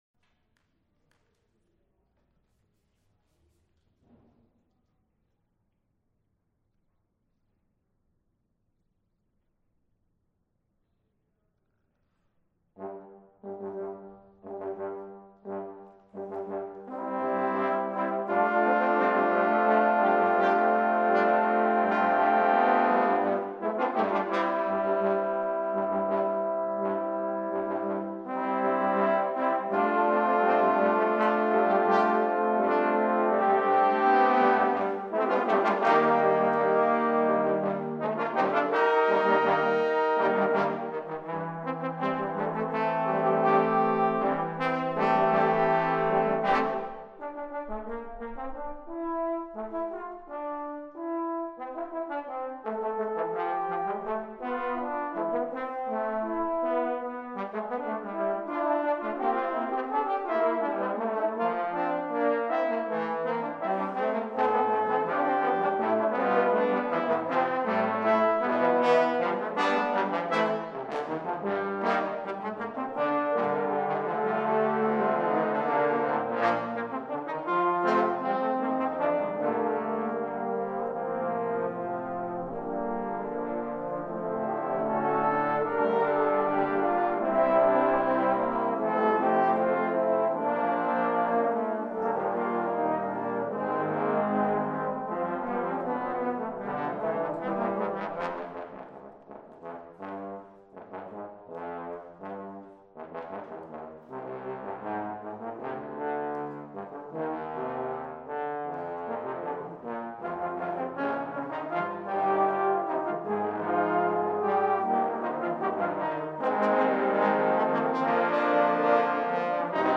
For Trombone Ensemble
8 Tbns .